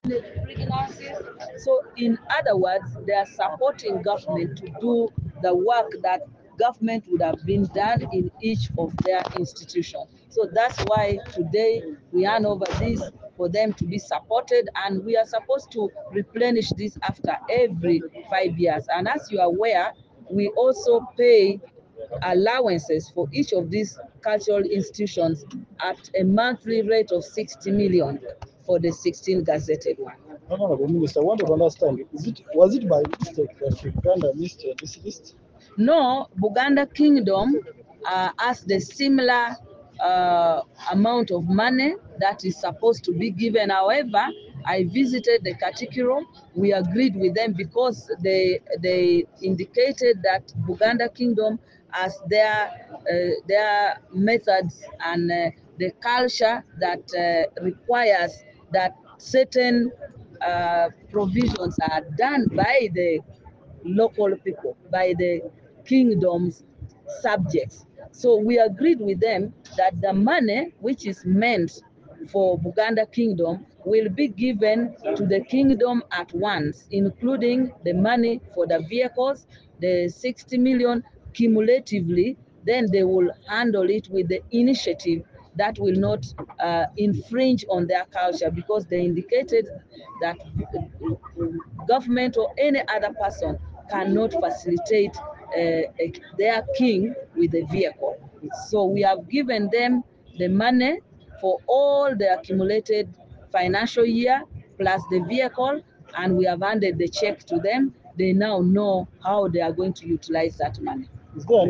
However, Amongi told journalists at Kololo that in meetings with Buganda officials, including the Katikkiro Charles Peter Mayiga, it had been agreed that the kingdom be given money instead of the cars.